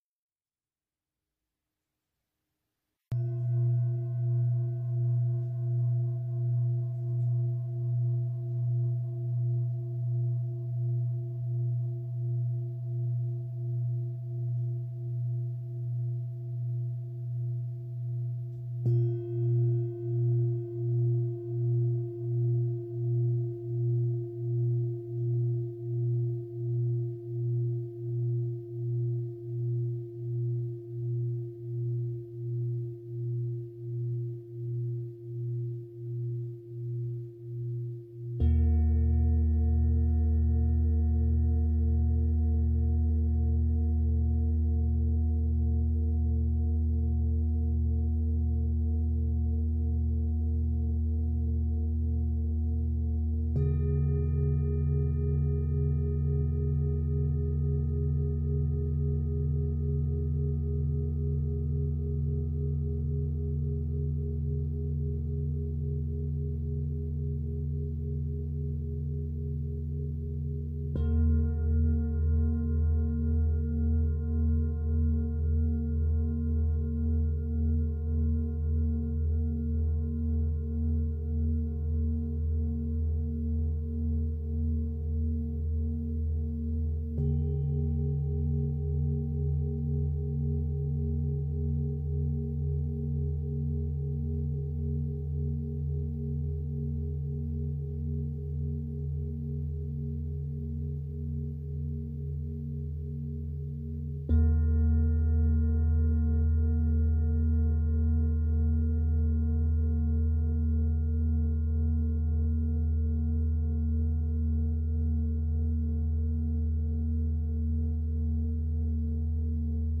Massage habillé aux bols chantants Peter Hess®
Les sons harmonieux apaisent l’esprit, les vibrations douces qui émanent du bol se propagent dans tout le corps.
Mon rituel quotidien de cinq minutes pour écouter et ressentir les vibrations du bol